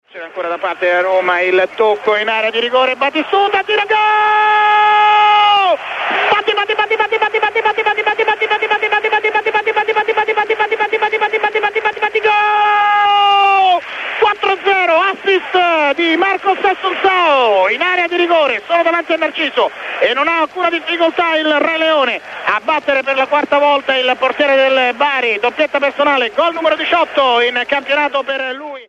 radiocronaca del goal